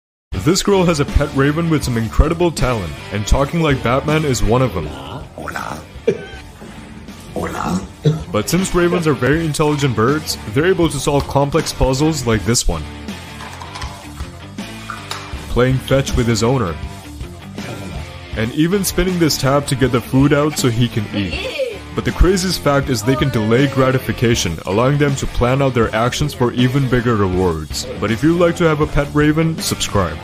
Her Raven Talks Like Batman!